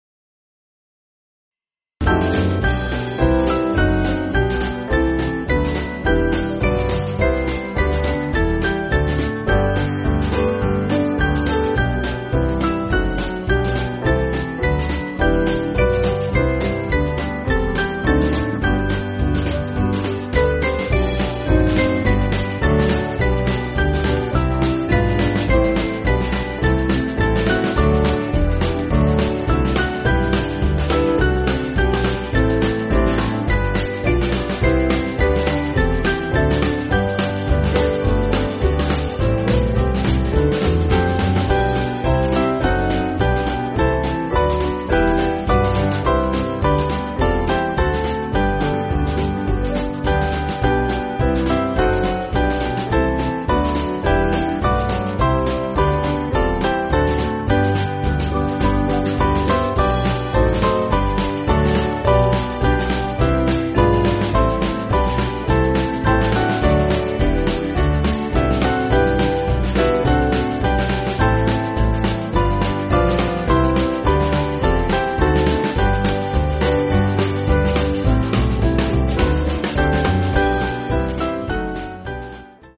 Small Band
(CM)   3/G